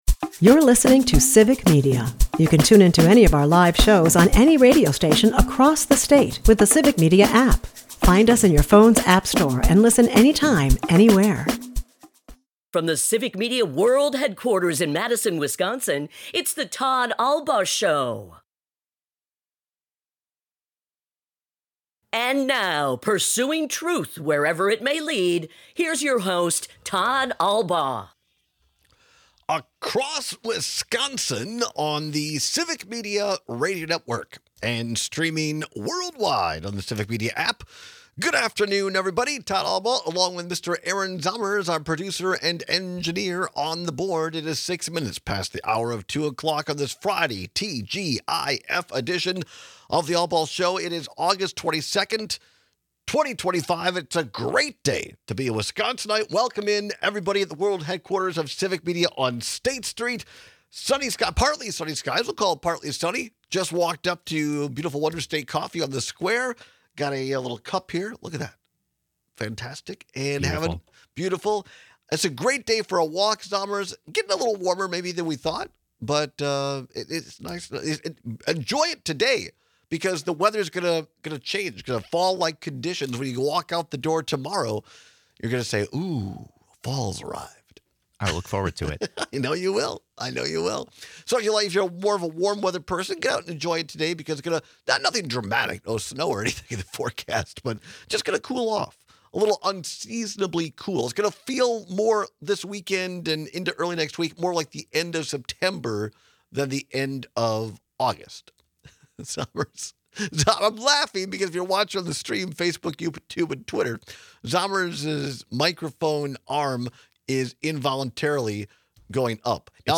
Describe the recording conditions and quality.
airs live Monday through Friday from 2-4 pm across Wisconsin